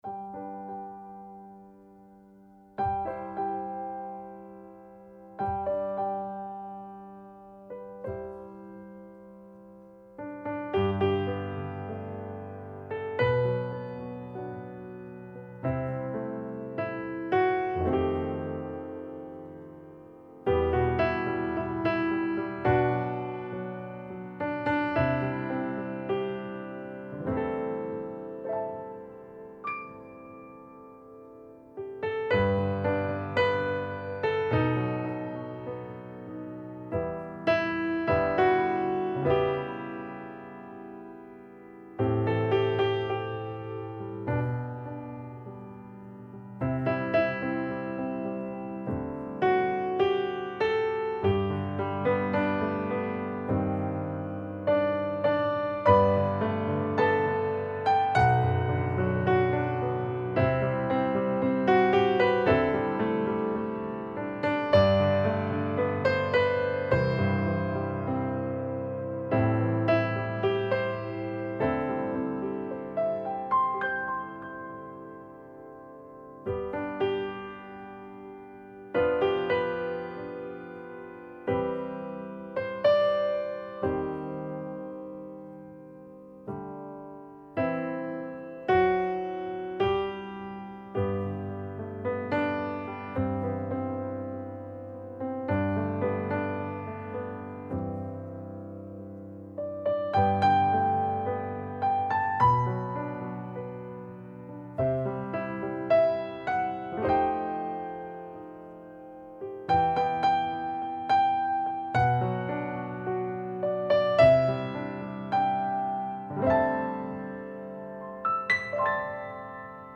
ソロピアノ